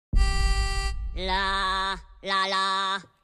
الأقسام : Sound Effects
تسميات : minion me despicable voice